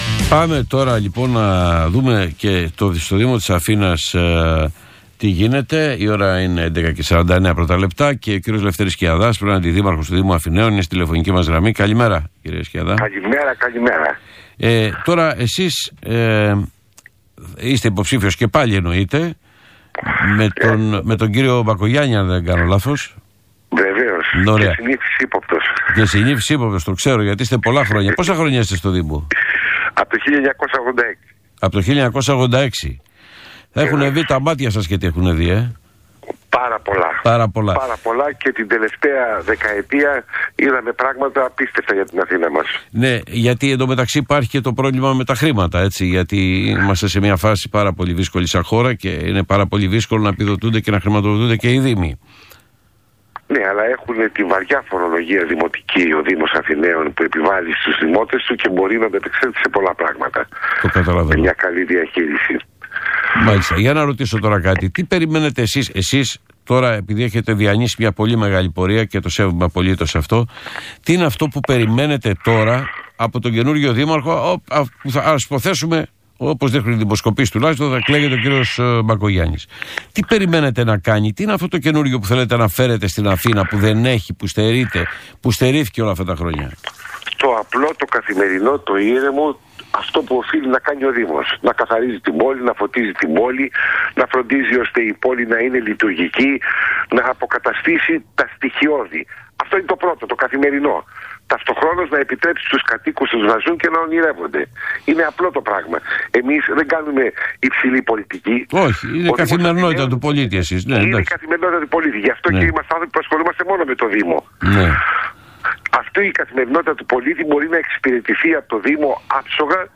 Συνέντευξη του Ελ. Σκιαδά στον ΣΚΑΪ 100,3